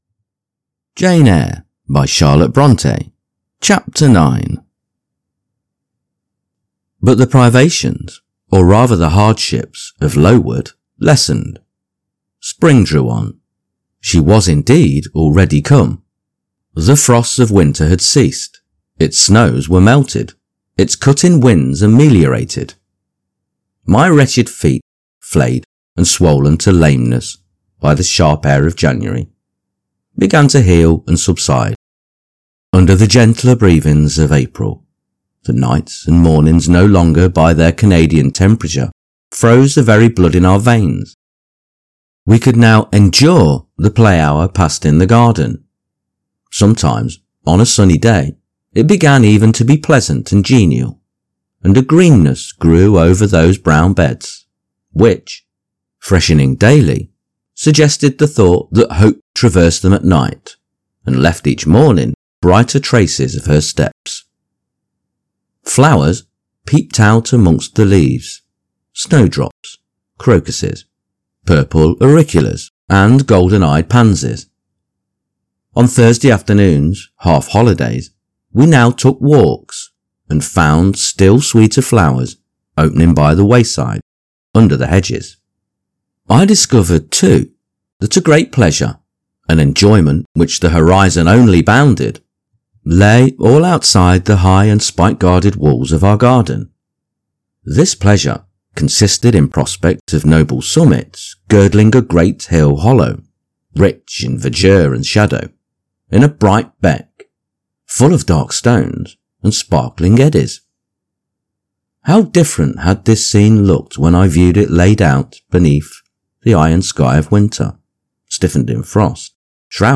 Jane Eyre – Charlotte Bronte – Chapter 9 | Narrated in English